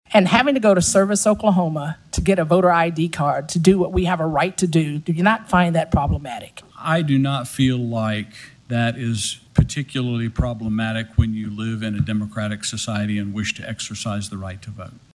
CLICK HERE to listen to commentary from Regina Goodwin.